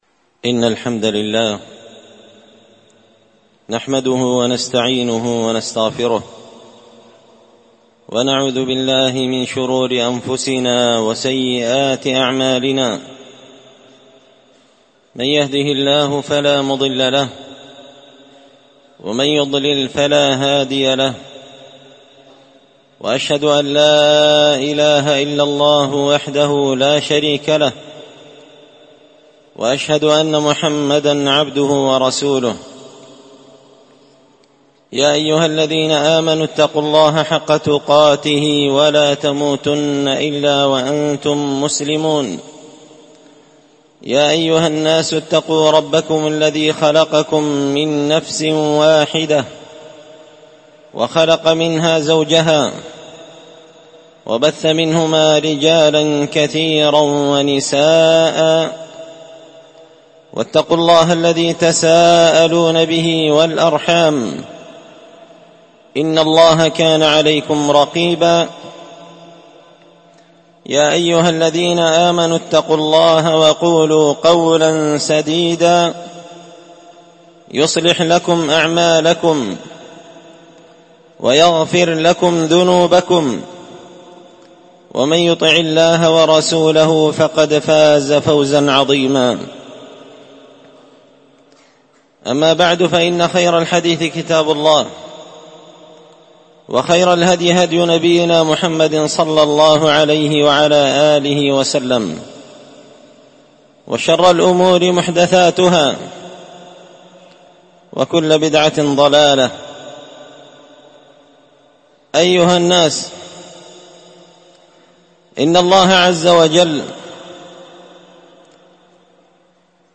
خطبة جمعة بعنوان لماذا لا يحتفل أهل السنة بليلة الإسراء والمعراج